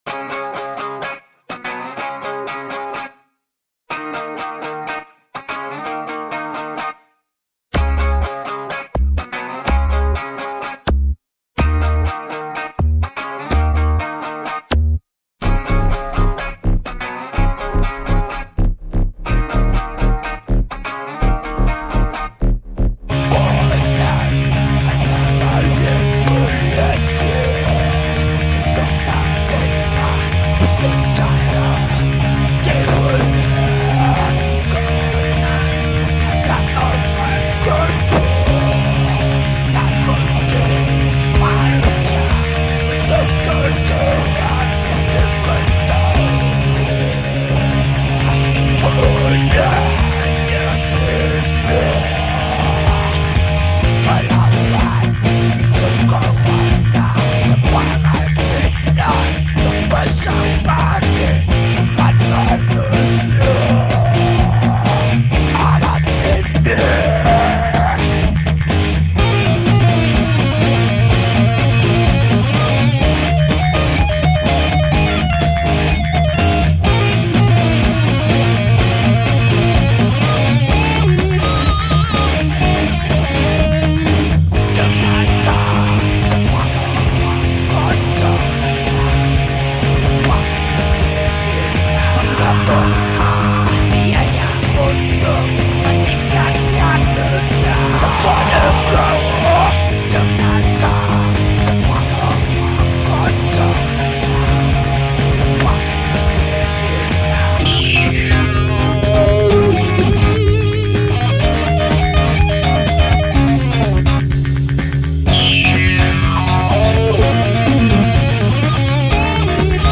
Home > Music > Rock > Running > Restless > Fast